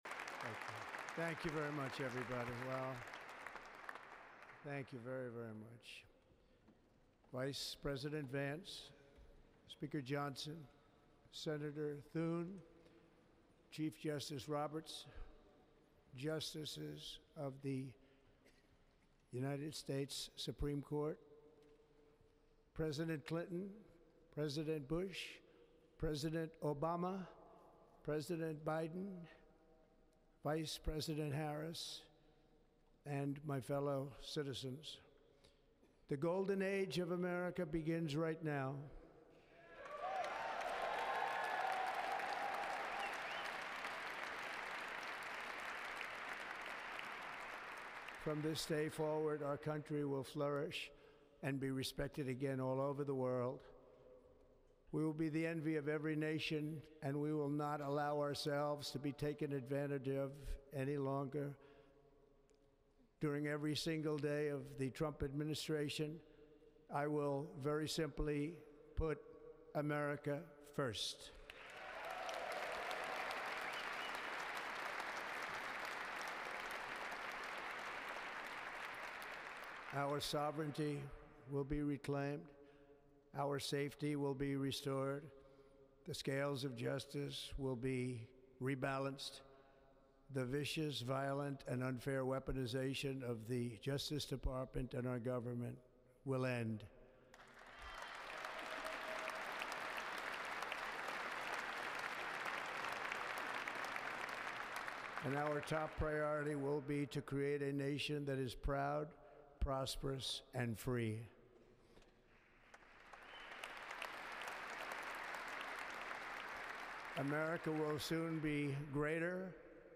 January 20, 2025: Inaugural Address | Miller Center
swearing_in_trump_47.mp3